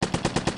现代战争 " 机关枪循环
描述：机枪火灾的声音设计，可以一遍又一遍地循环。
Tag: ADPP 战争 射击 军事 步枪 士兵 机枪 突击步枪 射击 武器 消防 军队 战斗 战争 射击 手枪